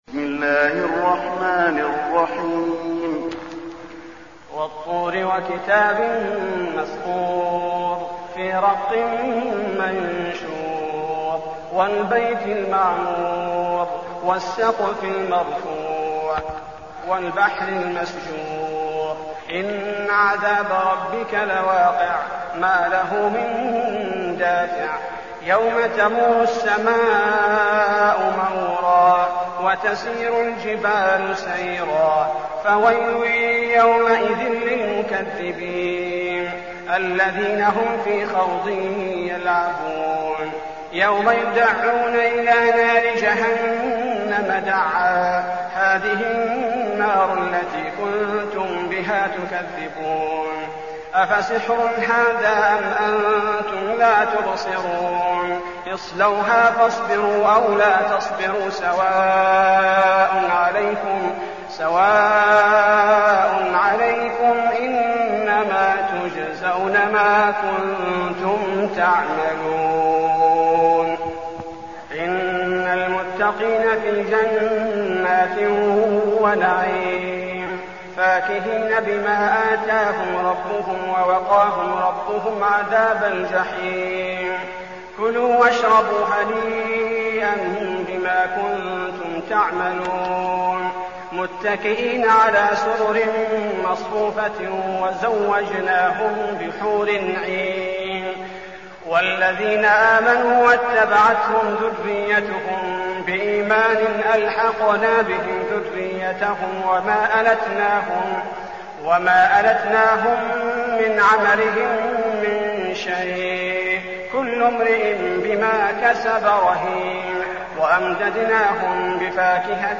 المكان: المسجد النبوي الطور